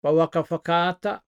ngāti porou